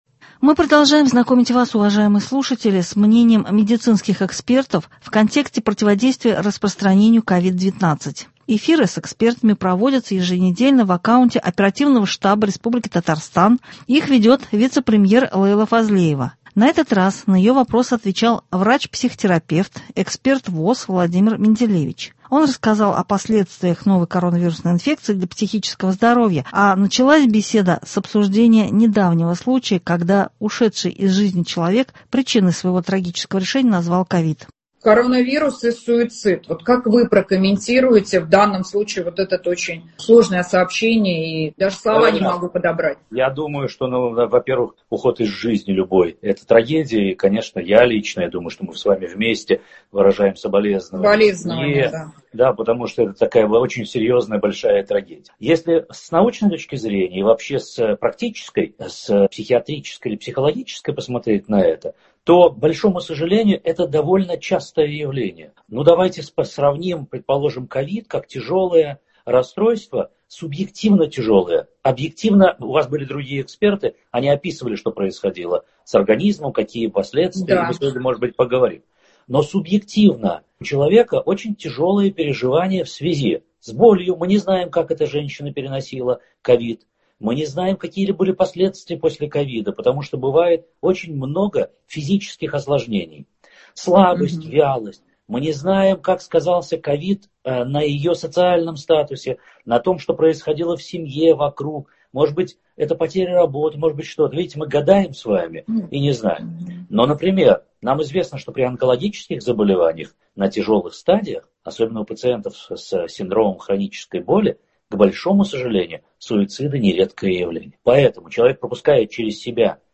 Эфиры с ними проводятся в аккаунте Оперативного штаба РТ по противодействию распространению COVID-19, их ведет вице- премьер Лейла Фазлеева.